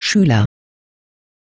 Dieses wird mit den Sprachdateien angesagt: "Lehrer" bzw. "Schüler".